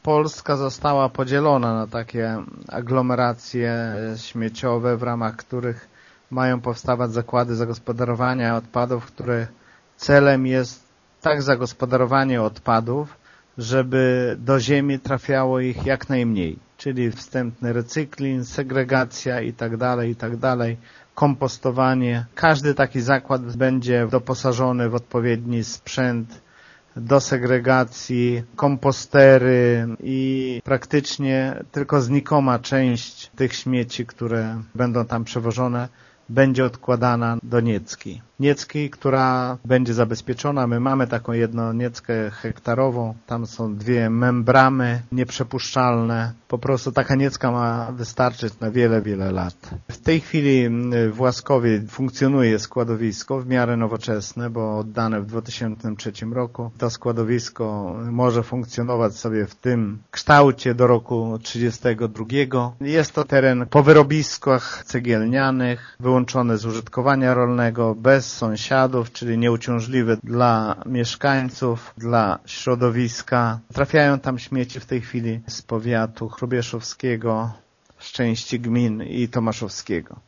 „Za te pieniądze ma powstać nowoczesny zakład, który będzie się zajmować zagospodarowaniem śmieci z całej aglomeracji hrubieszowsko-tomaszowskiej” – tłumaczy wójt Gminy Mircze Lech Szopiński: